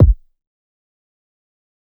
Rack Kick6.wav